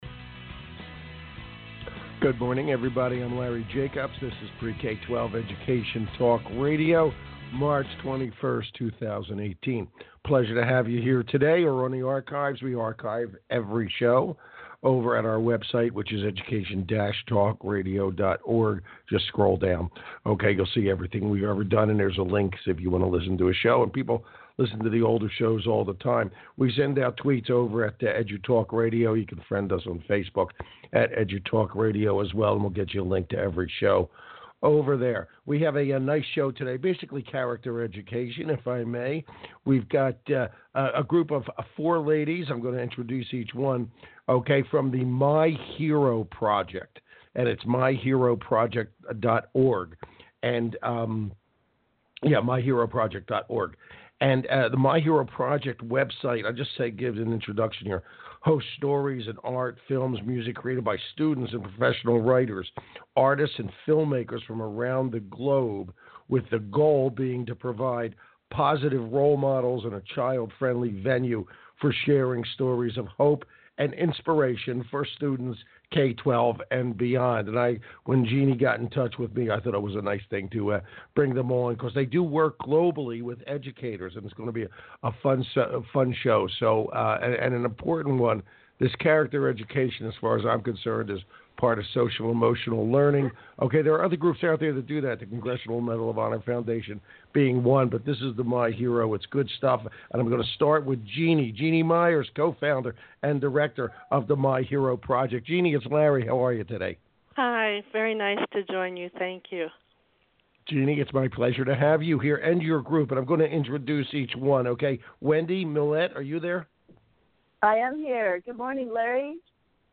MY HERO on Talk Radio_01.mp3